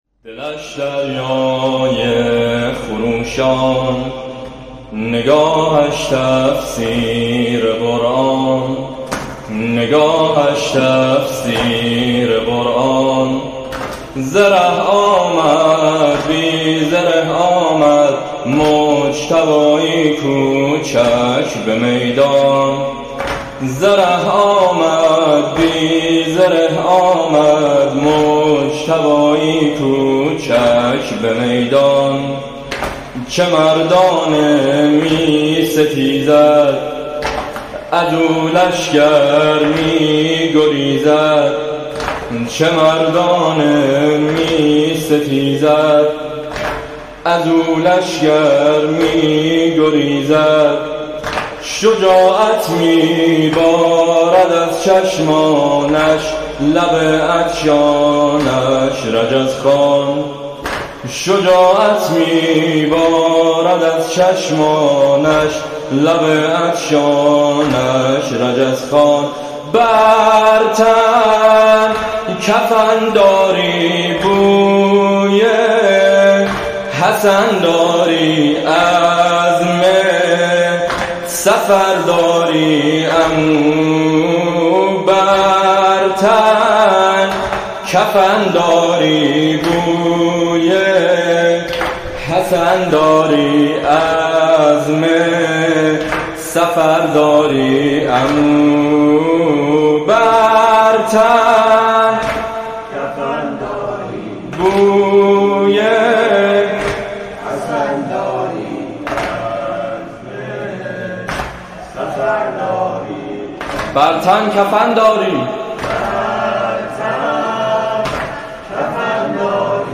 شب ششم محرم 1401
1 0 نوحه دلش دریای خروشان نگاهش تفسیر قرآن